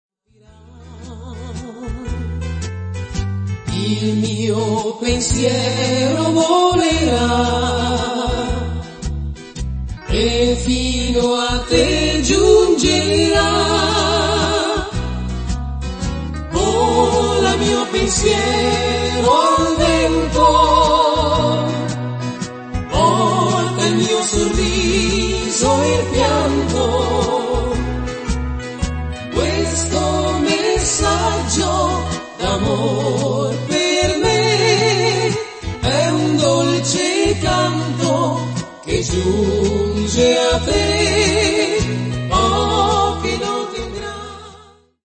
ballata